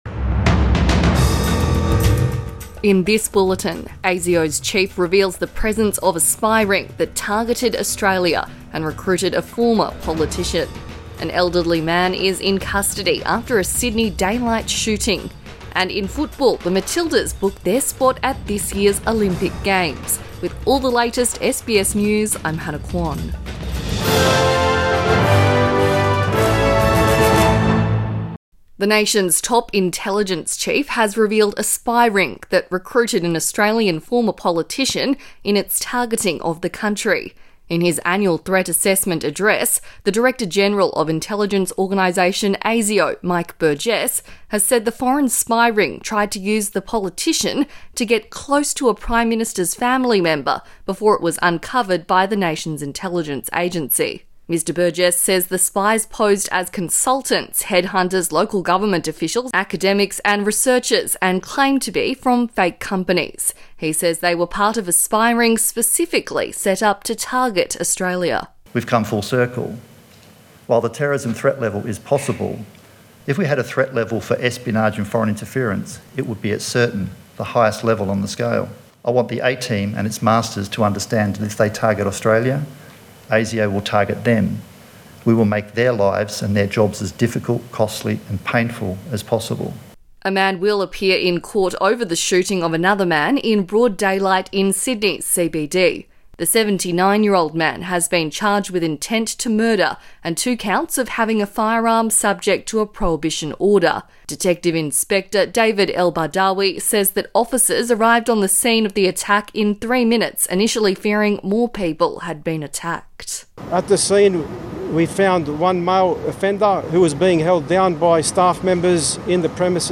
Morning News Bulletin 29 February 2024